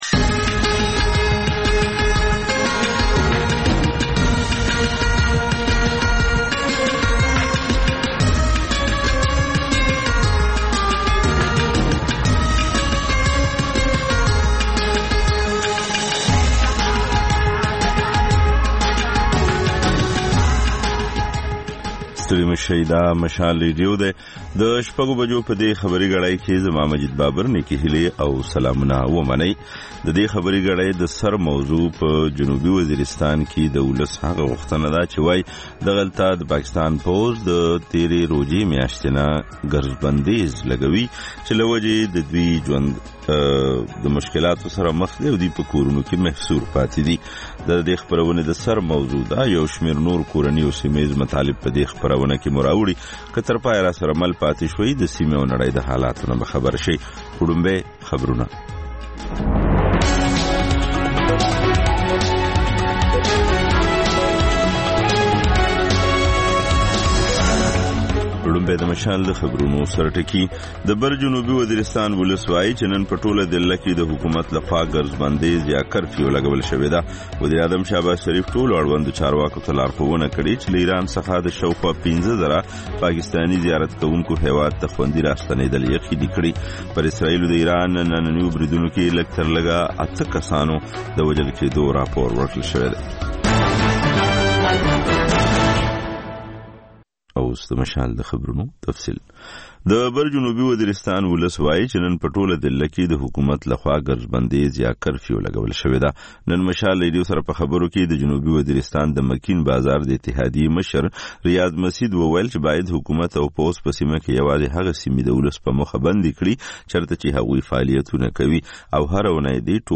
دویمه خبري ګړۍ تکرار